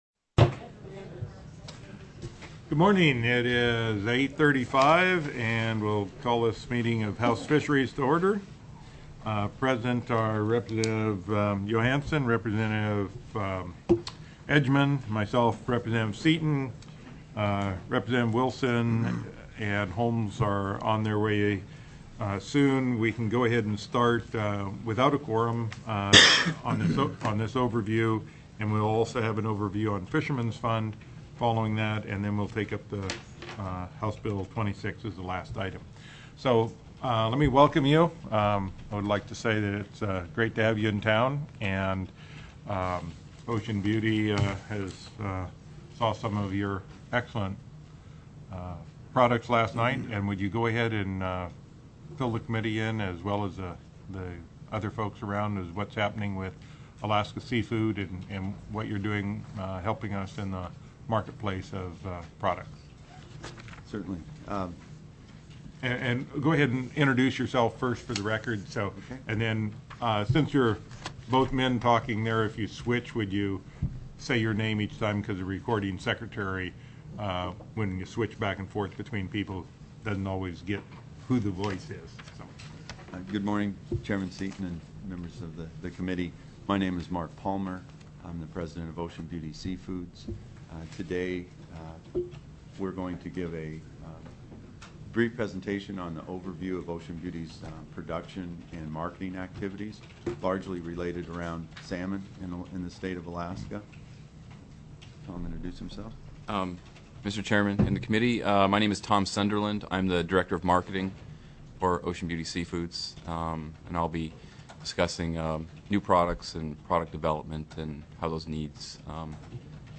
02/02/2007 08:30 AM House FISHERIES
TELECONFERENCED Heard & Held